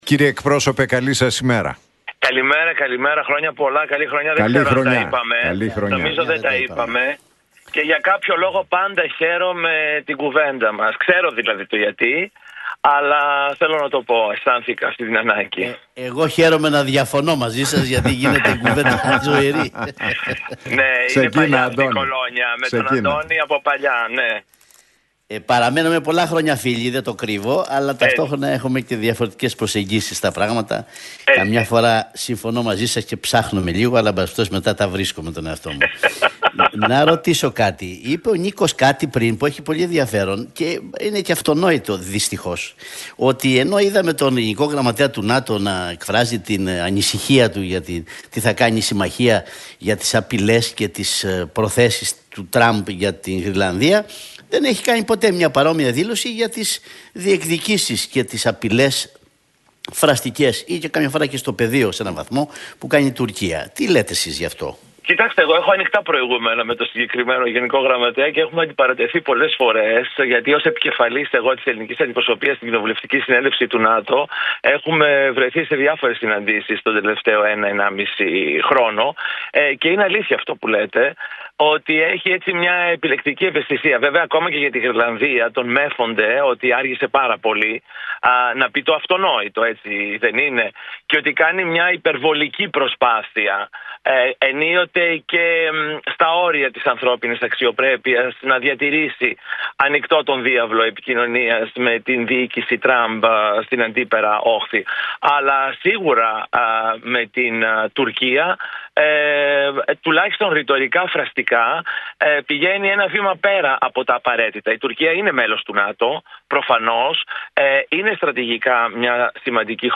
O Kοινοβουλευτικός Eκπρόσωπος της ΝΔ, Δημήτρης Καιρίδης μίλησε στον Νίκο Χατζηνικολάου